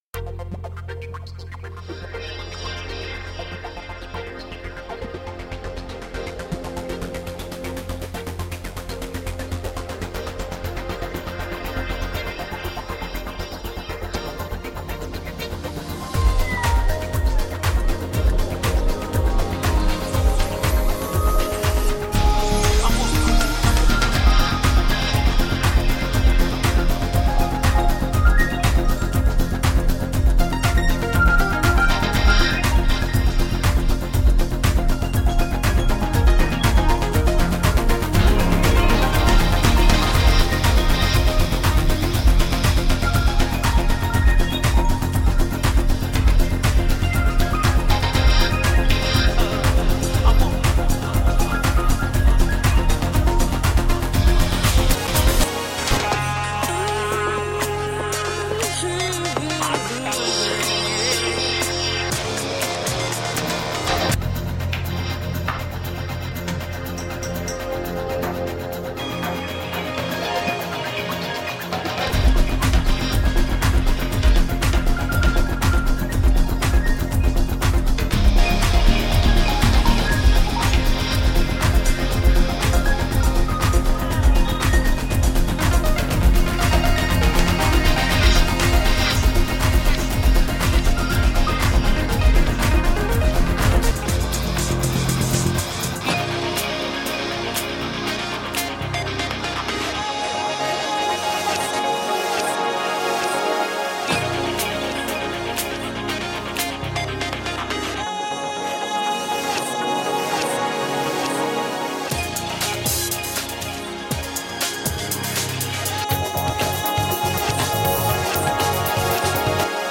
Energetic and organic clubhouse.
Tagged as: Electronica, Other